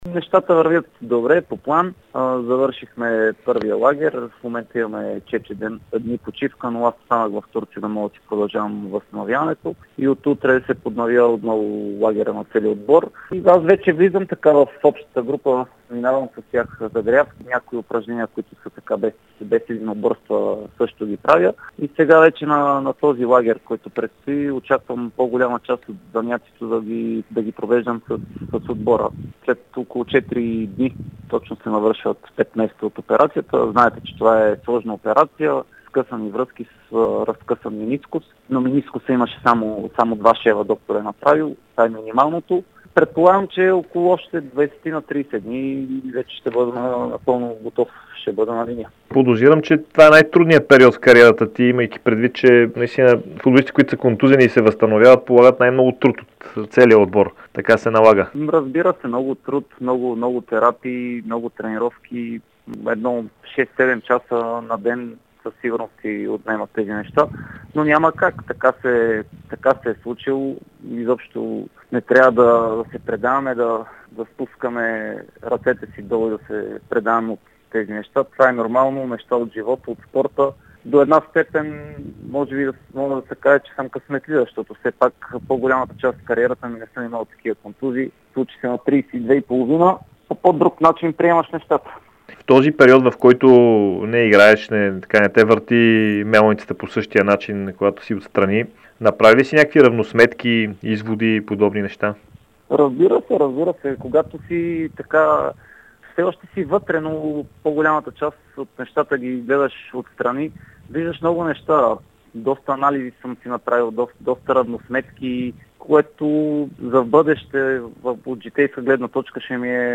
Трикратният носител на приза „Футболист на годината“ и бивш национал Ивелин Попов говори пред Дарик радио и dsport относно своето възстановяване от тежка контузия. Той засегна и други интересни въпроси свързания с българския футбол.